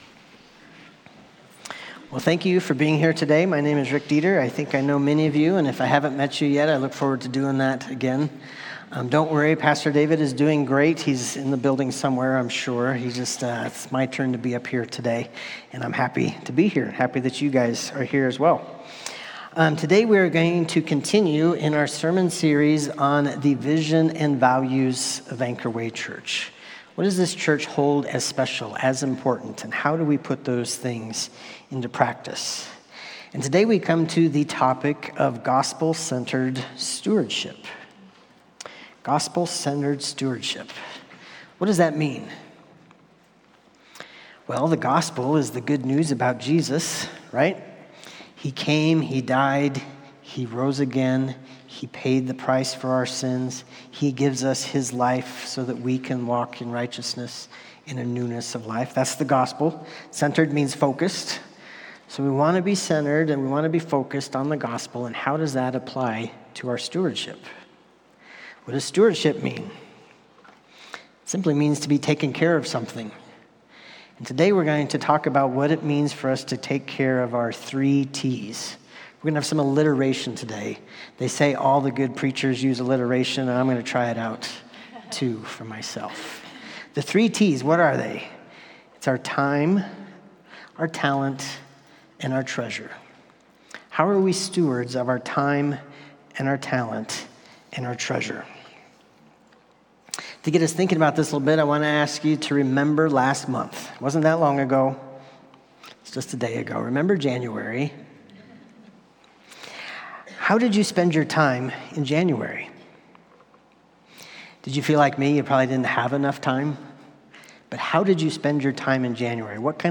Sermons | Anchor Way Church